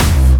VEC3 Bassdrums Dirty 26.wav